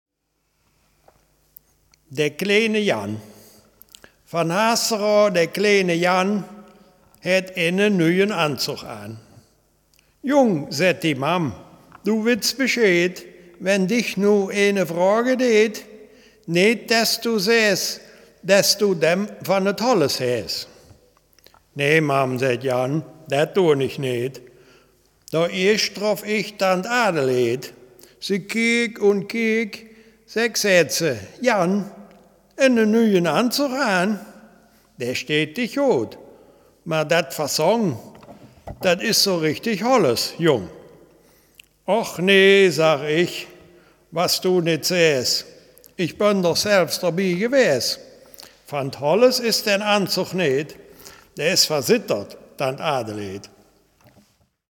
Selfkant-Platt
Geschichte